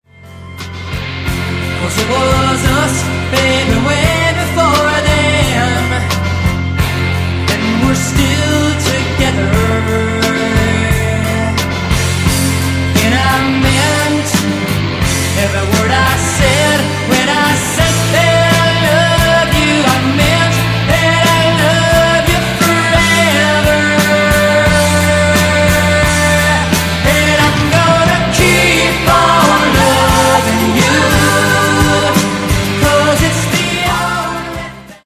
Genere:   Pop | Rock | Dance